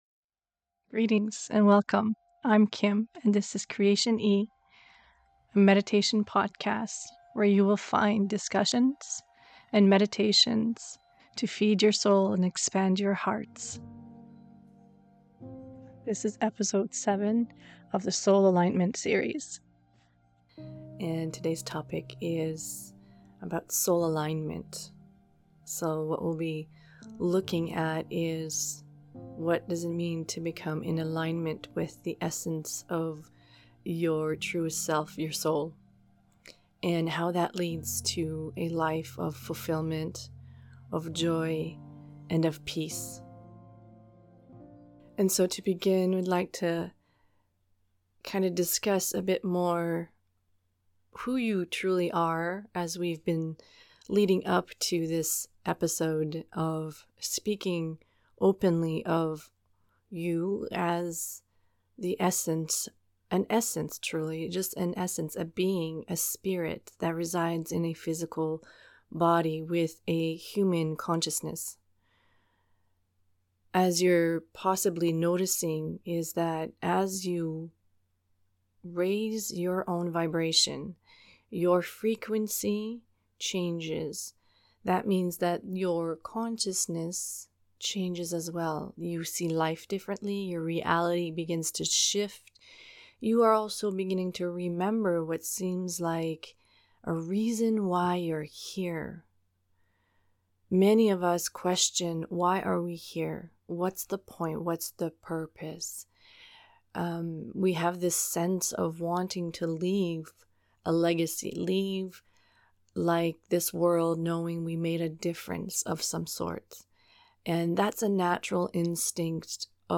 Key topics in this conversation include: Being the essence of God, of the creator, self-realization, fulfillment and purpose. The guided meditation that follows opens you to a greater state of consciousness as you bring your mind into alignment with your heart.